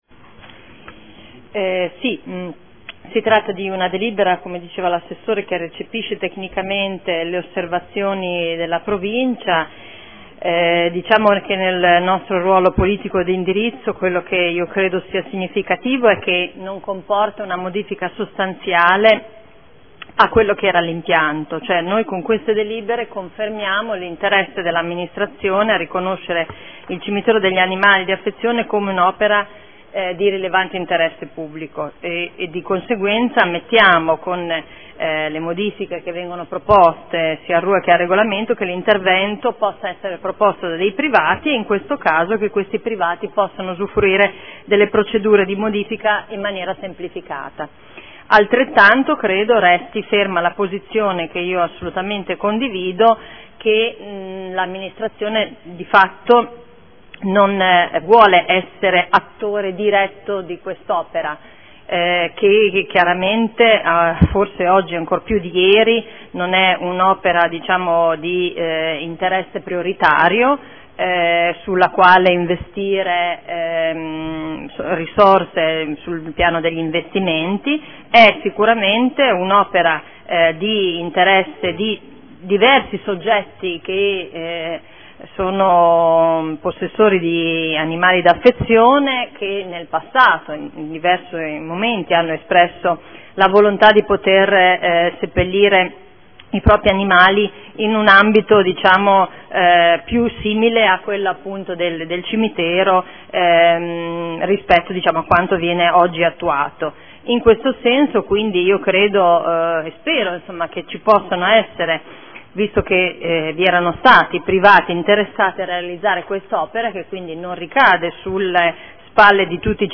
Audio Consiglio Comunale